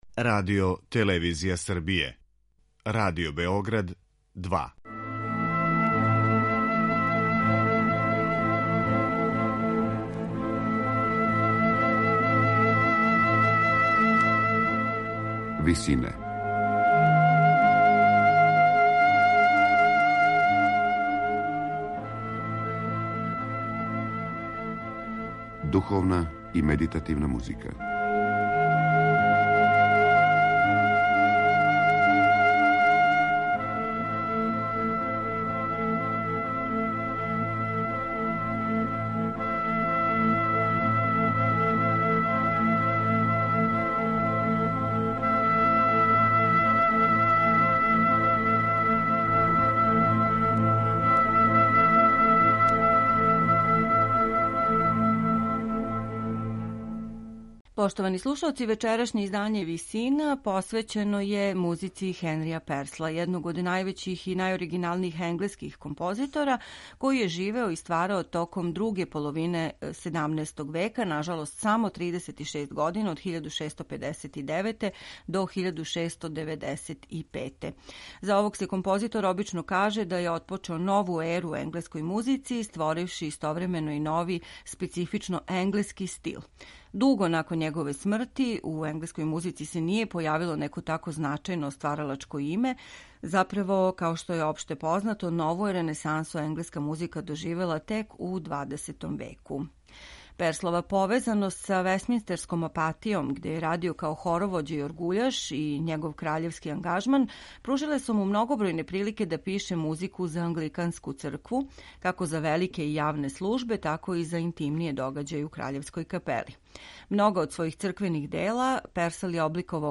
Хенри Персл, две духовне химне